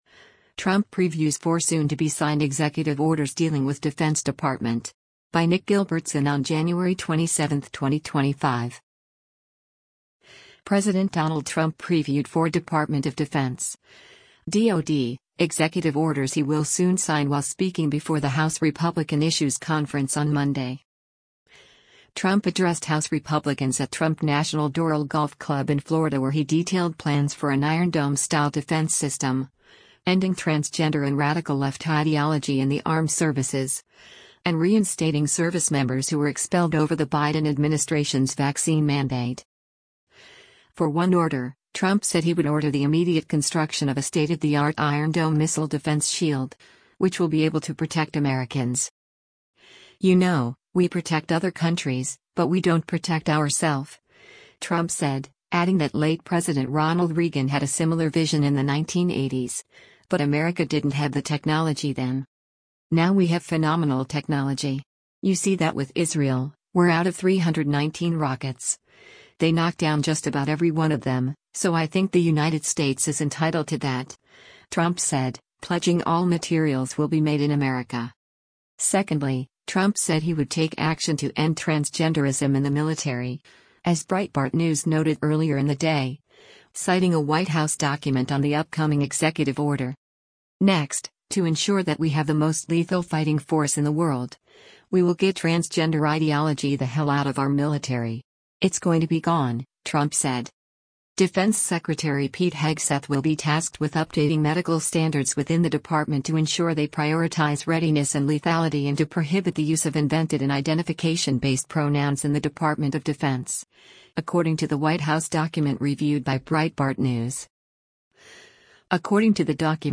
President Donald Trump previewed four Department of Defense (DOD) executive orders he will soon sign while speaking before the House Republican Issues Conference on Monday.
“Finally, we will offer full reinstatement to any service member who was expelled from the armed forces due to the COVID vaccine mandate, and we will restore them to their former rank with full pay,” he said to applause.